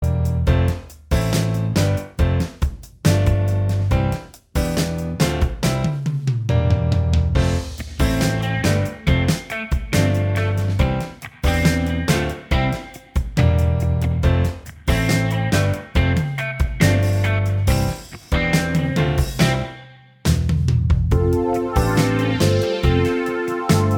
Minus All Guitars Pop (1970s) 4:45 Buy £1.50